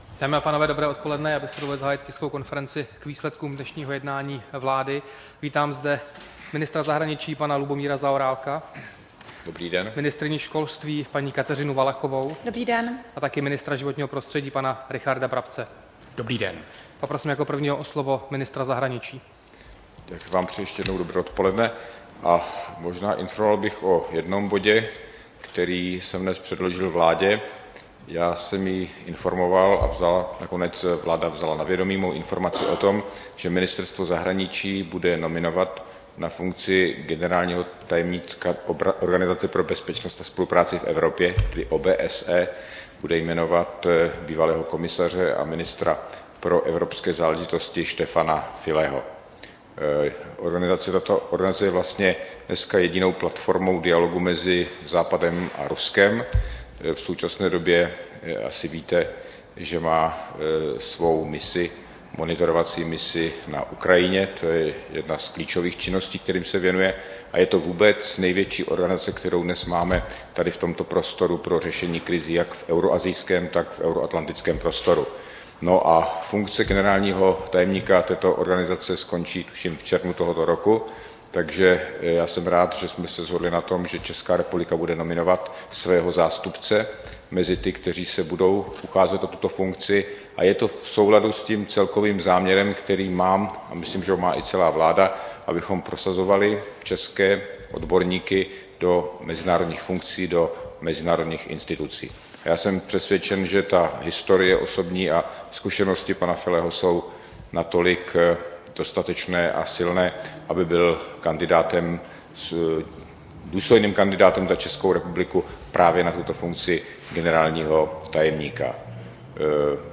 Tisková konference po jednání vlády, 15. února 2017